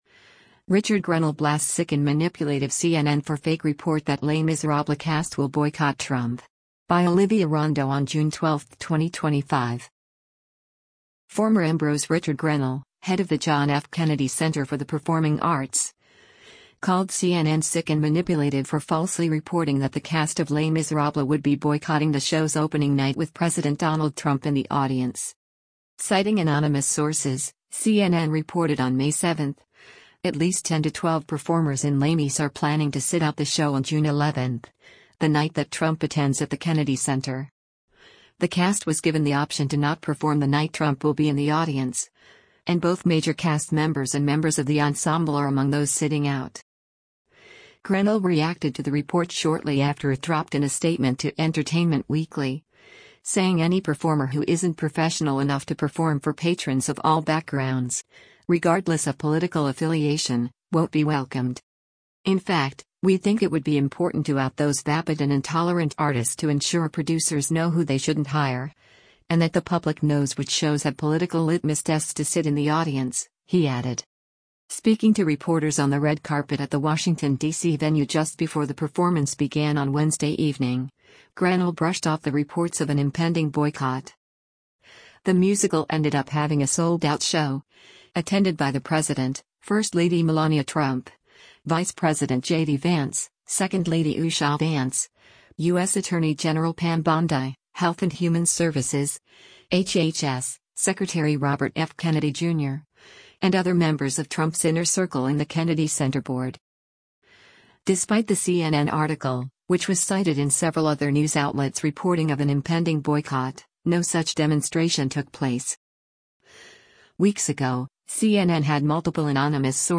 Speaking to reporters on the red carpet at the Washington, DC venue just before the performance began on Wednesday evening, Grenell brushed off the reports of an impending boycott: